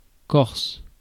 Corsica (/ˈkɔːrsɪkə/ KOR-sik-ə, Corsican: [ˈkorsiɡa, ˈkɔrsika], Italian: [ˈkɔrsika]; French: Corse [kɔʁs]
Fr-corse.ogg.mp3